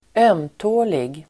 Uttal: [²'öm:tå:lig]